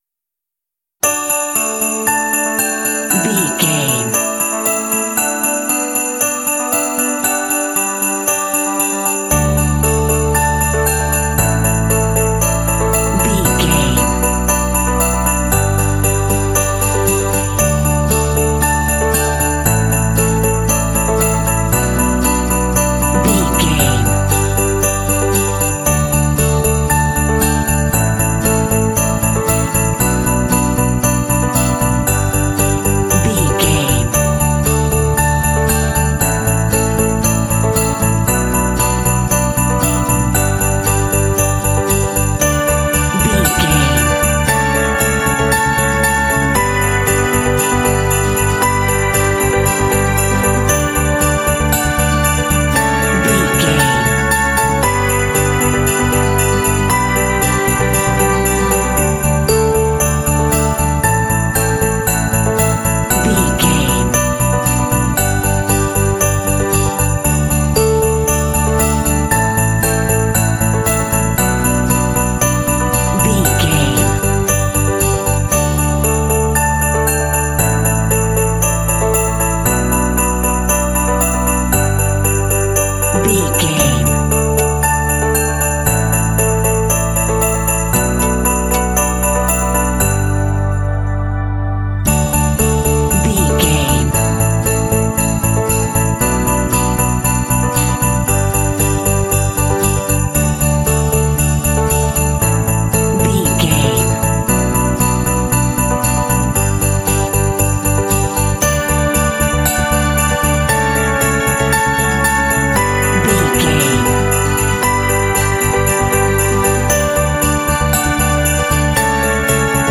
Ionian/Major
D
happy
uplifting
bouncy
festive
piano
bass guitar
acoustic guitar
strings
contemporary underscore